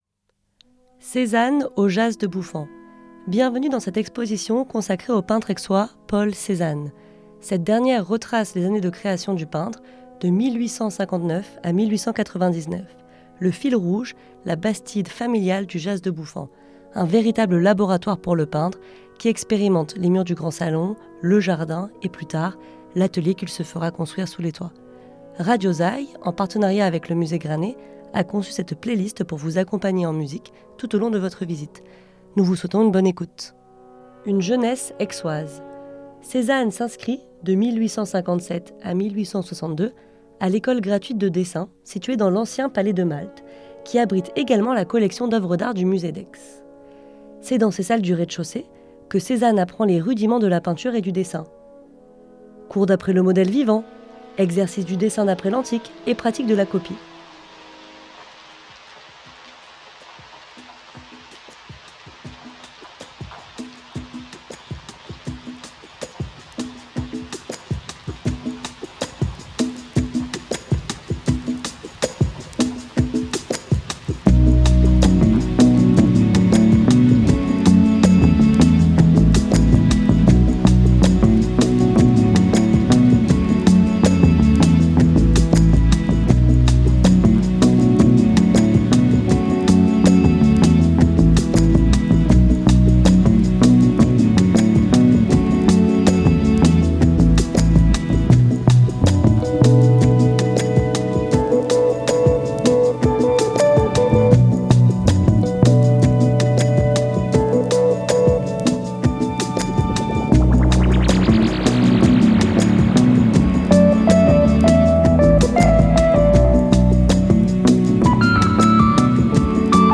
Plutôt jazz, funk, ou electronique ?